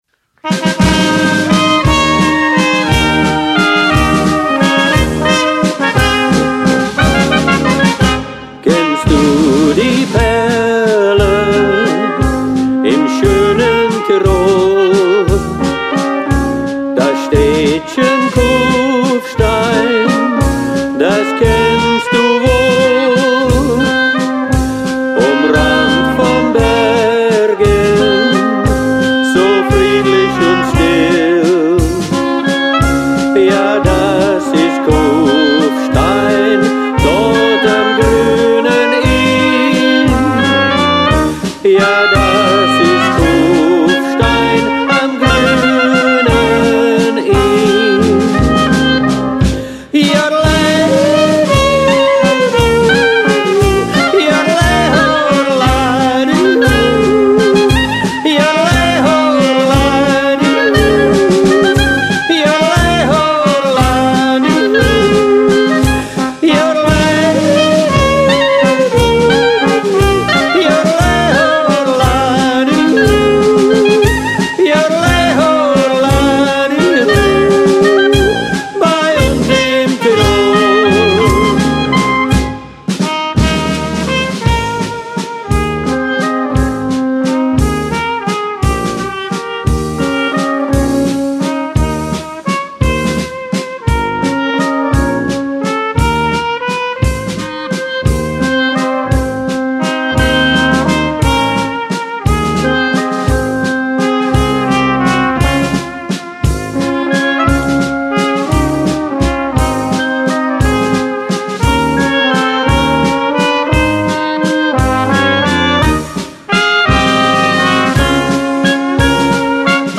German Oompah band for hire
Vocals, Trumpet, Saxophone, Trombone, Drums
oompah-wunderbar-das-kufsteiner-lied.mp3